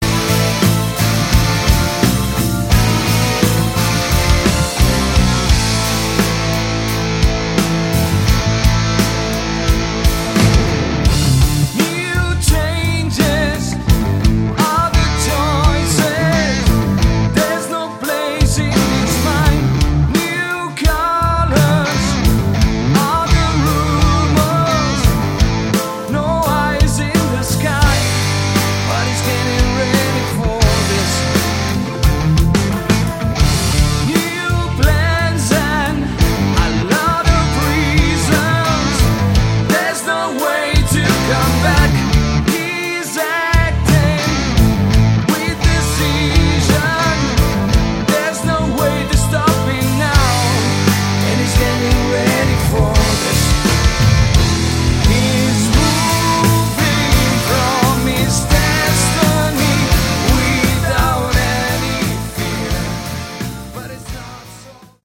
Category: Melodic Rock
lead and backing vocals
guitars, keyboards, violin